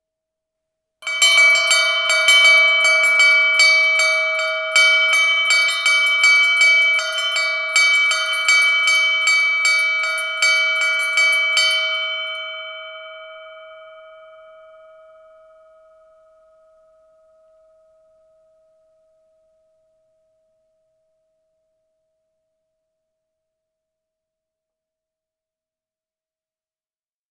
Chiming clock.
12 bell bells belltower cathedral chapel chime chiming sound effect free sound royalty free Animals